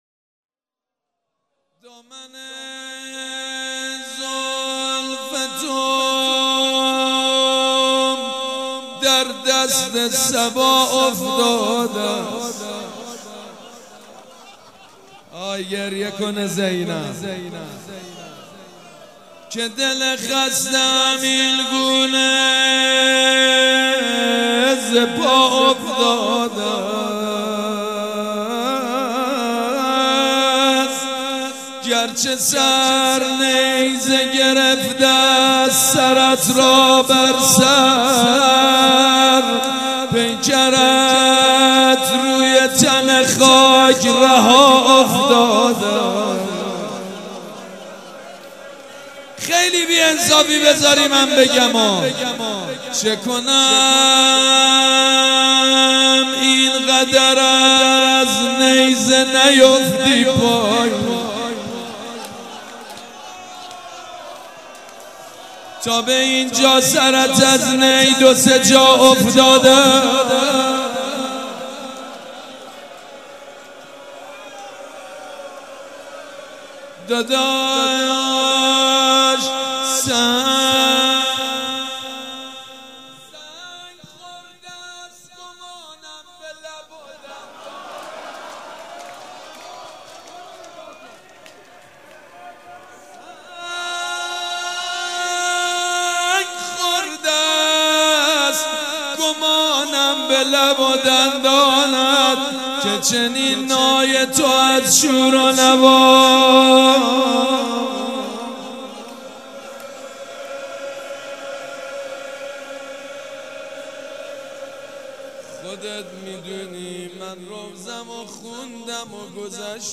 مراسم شب اول ماه صفر | یکشنبه ۳۰ مهرماه ۱۳۹۶
روضه
مداح
مراسم عزاداری شب اول